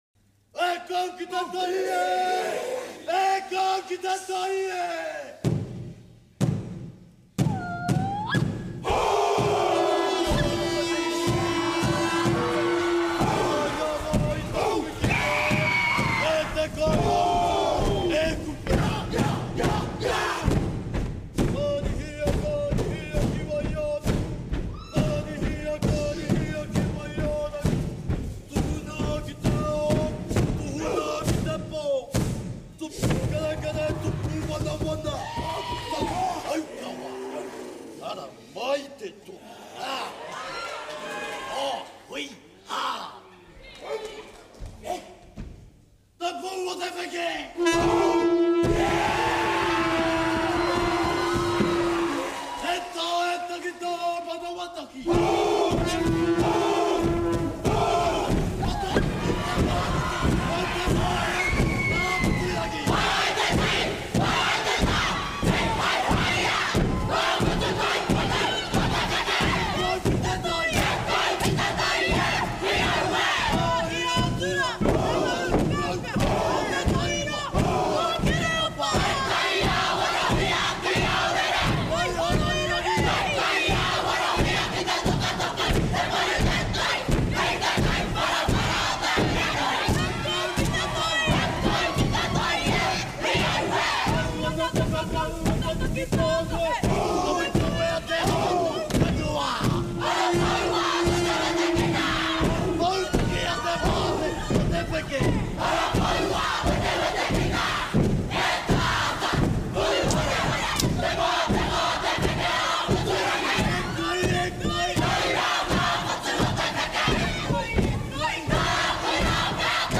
Kia kaha te haka. Ngā Kapa Haka Kura Tuarua o Aotearoa | Live and Exclusive on MĀORI+ and Te Reo Download MĀORI+ app and create a login to stream free.